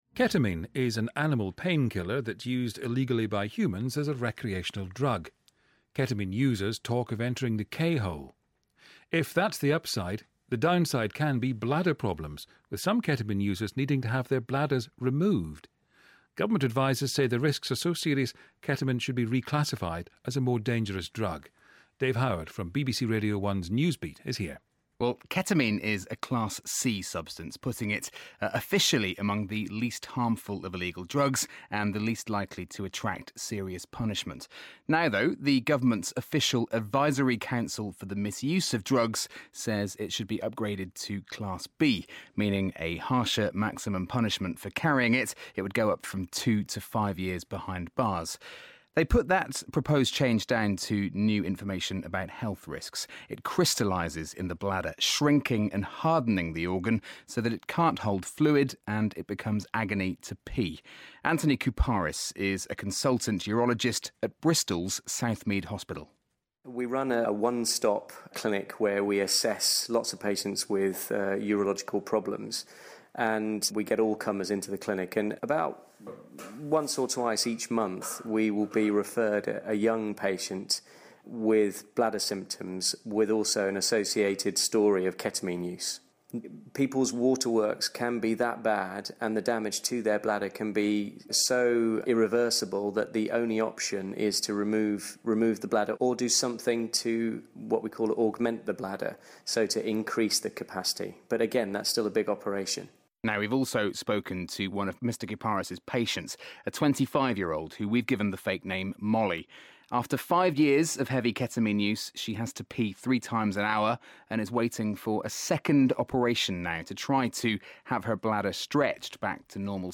We've been speaking to long-term users in Bristol about how the drug affects them.